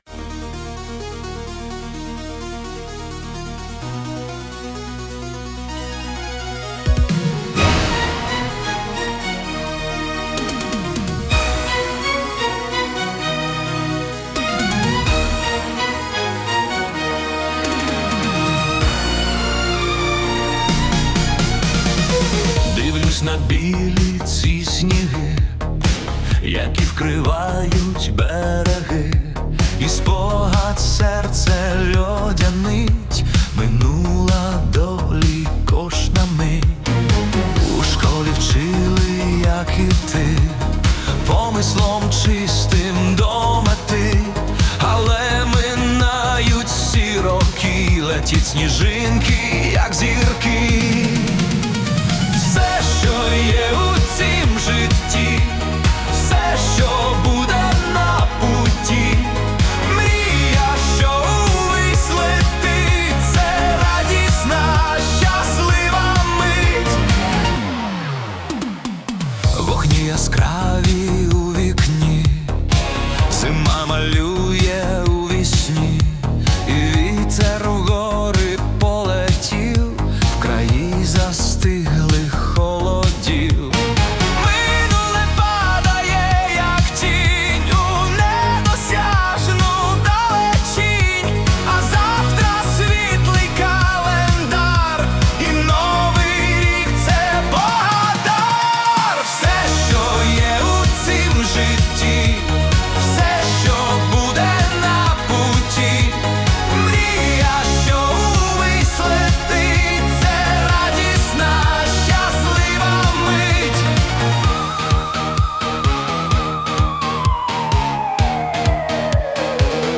Dark Euro-Disco / Synth-Pop